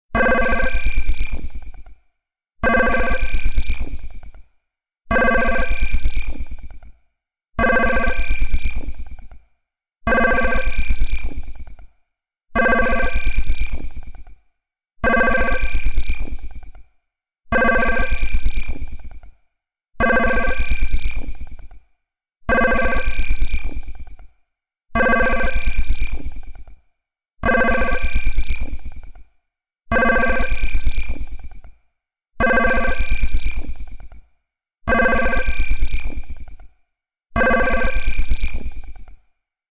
宇宙空間から聞こえるような、不思議な音が鳴ります。